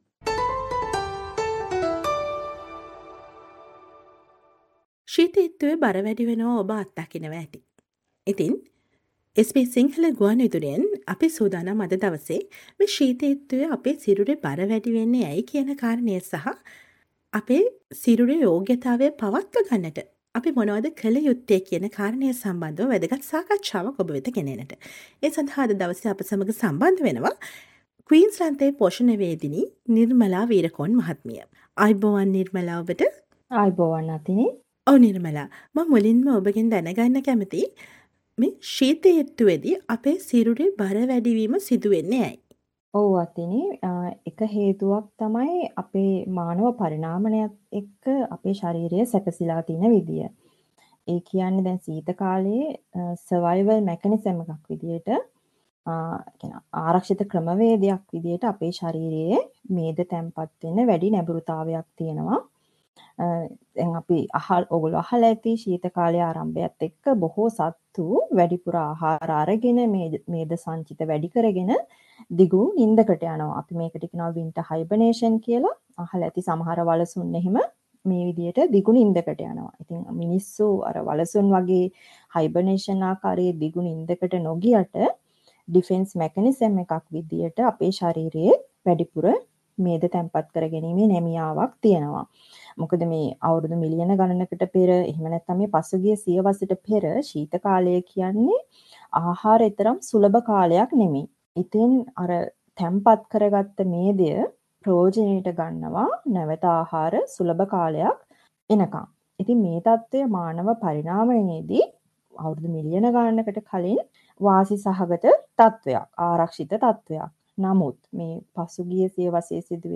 SBS Sinhala radio interview